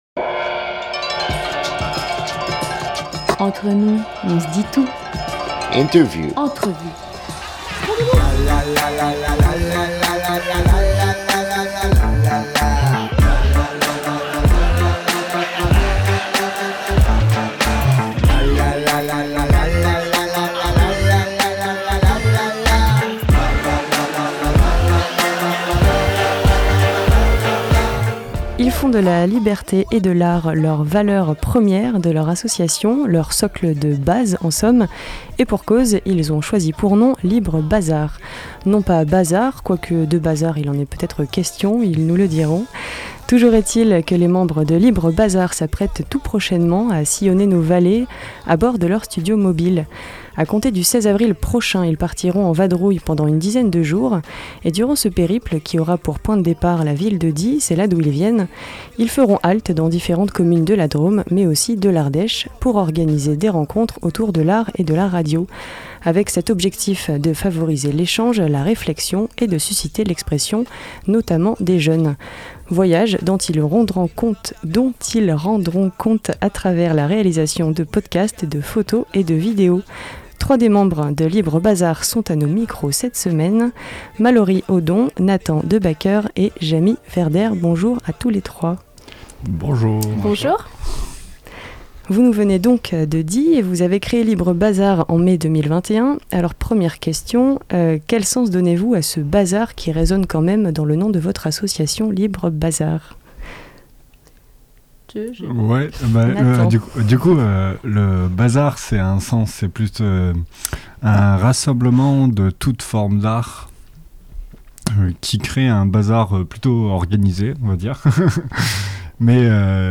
10 avril 2022 18:00 | Interview